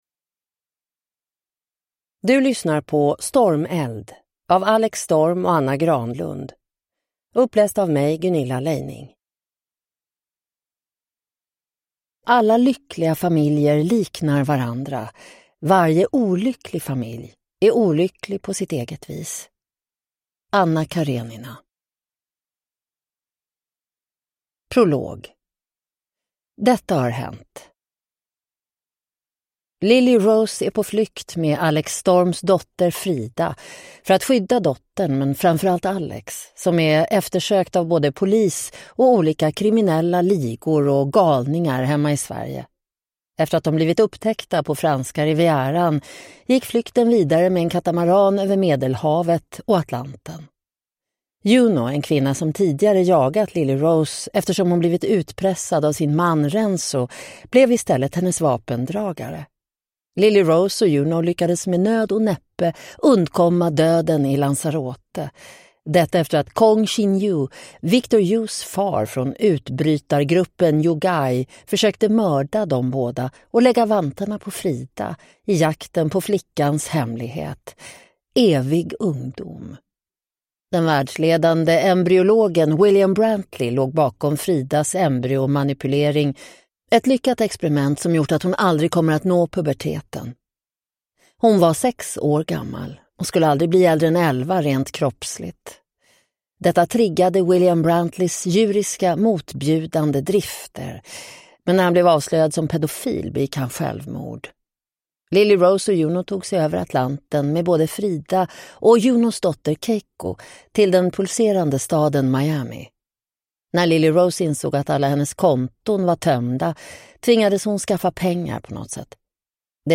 Stormeld (ljudbok) av Anna Granlund